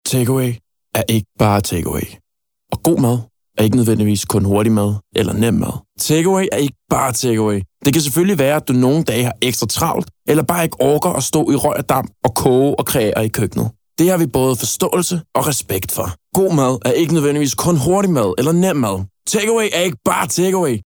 Mand
20-30 år
Reklame 3